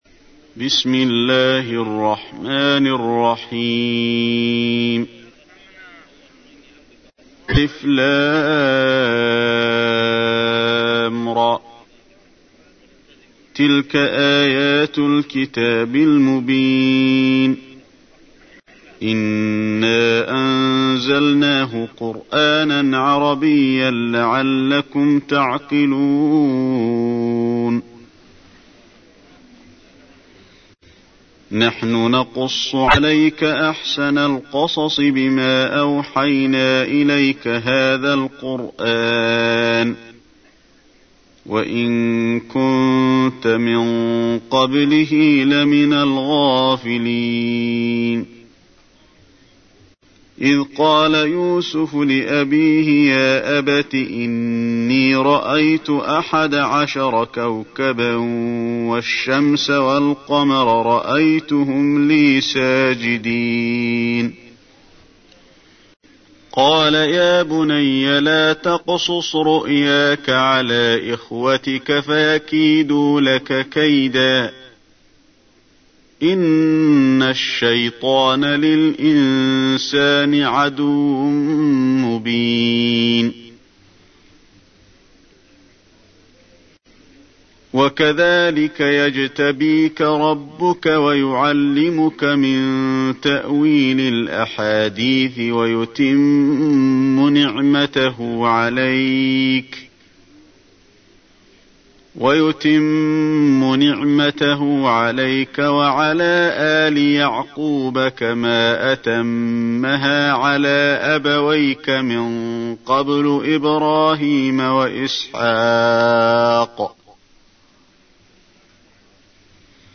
تحميل : 12. سورة يوسف / القارئ علي الحذيفي / القرآن الكريم / موقع يا حسين